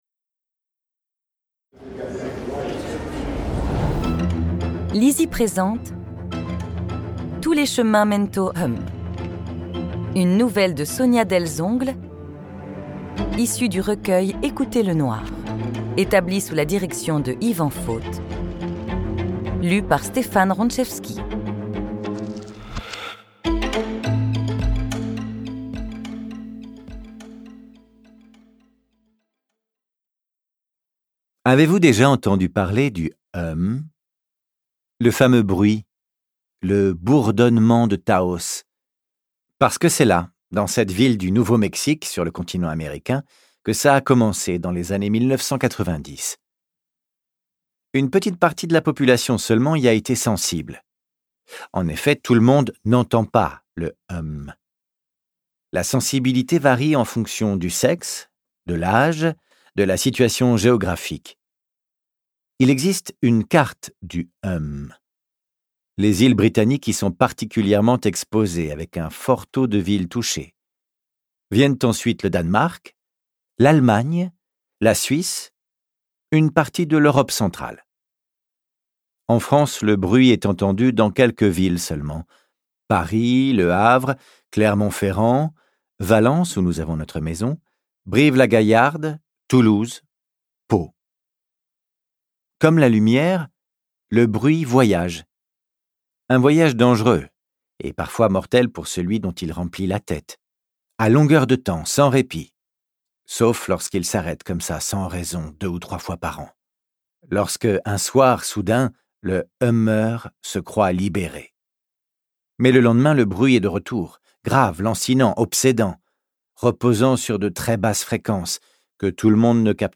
Extrait gratuit - Tous les chemins mènent au hum de Sonja DELZONGLE